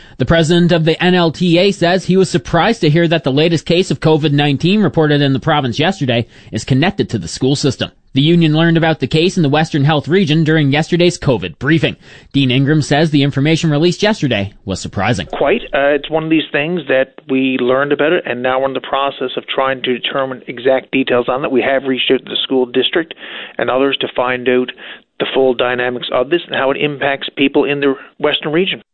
Media Interview - VOCM 1pm News Dec. 12, 2020